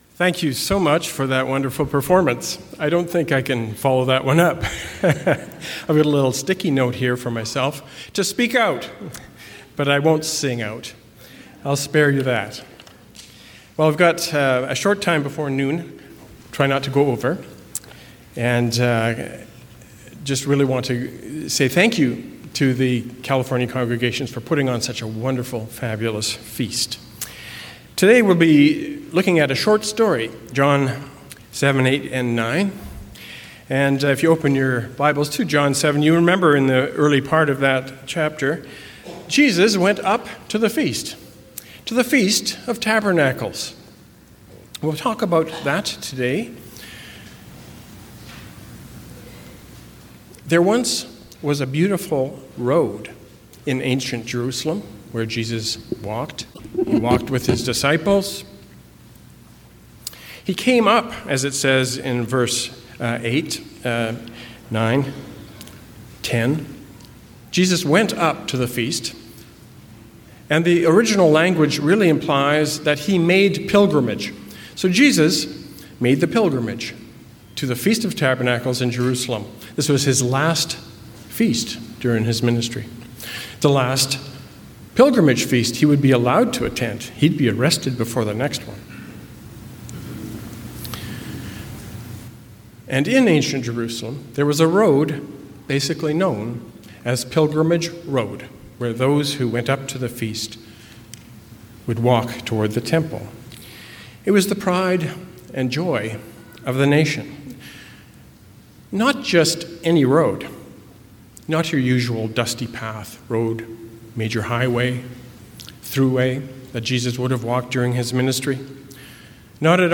Sermons
Given in Temecula, California